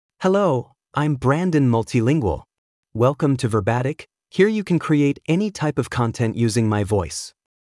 MaleEnglish (United States)
Brandon Multilingual is a male AI voice for English (United States).
Voice sample
Listen to Brandon Multilingual's male English voice.
Brandon Multilingual delivers clear pronunciation with authentic United States English intonation, making your content sound professionally produced.